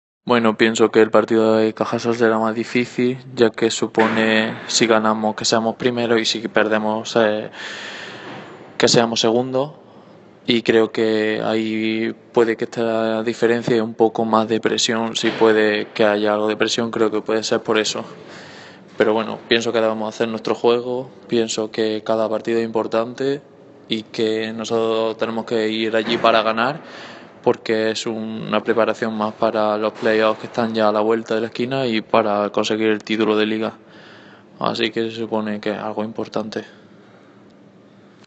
diferentes declaraciones